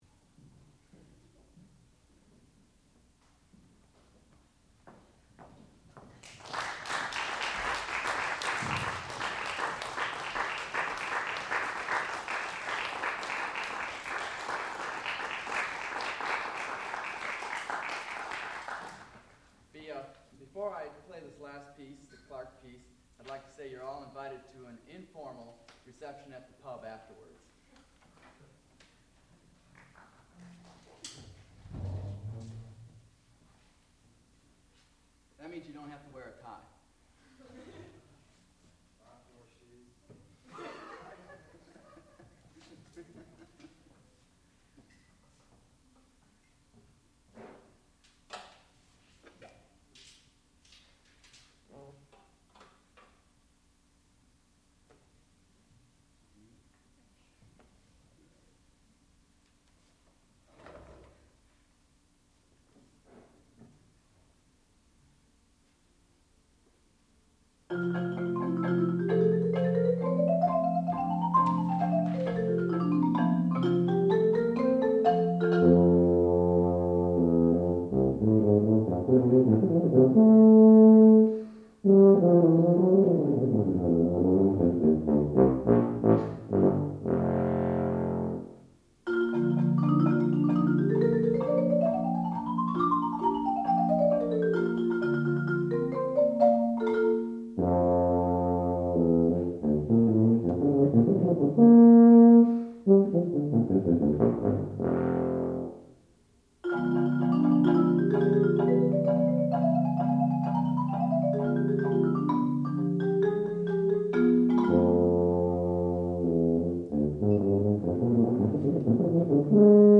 sound recording-musical
classical music
marimba
Master's Recital